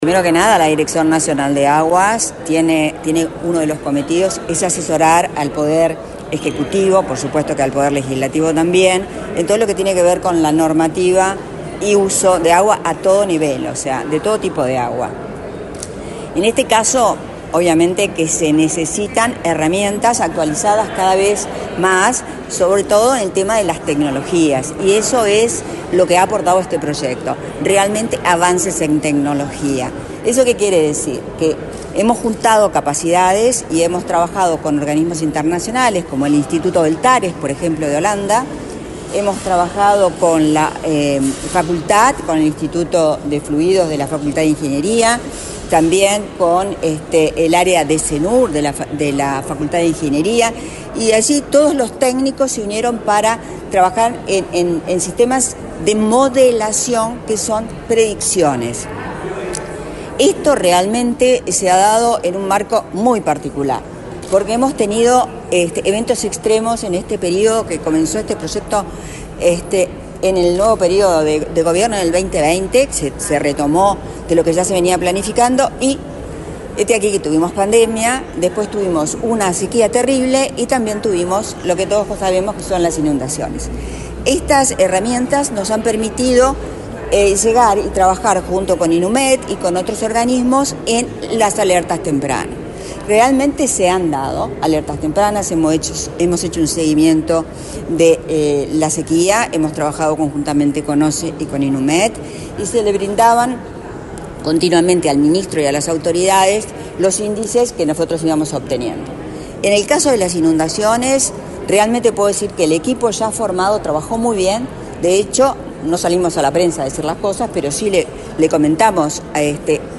Declaraciones de la directora nacional de Aguas, Viviana Pesce
Este lunes 8 en la Torre Ejecutiva, la directora nacional de Aguas, Viviana Pesce, dialogó con la prensa, antes de participar en la presentación de los resultados del proyecto implementado en la cuenca del Santa Lucía “Tecnología y modelación para la gestión integrada de las aguas como adaptación al cambio climático de la principal fuente de agua potable de Uruguay”.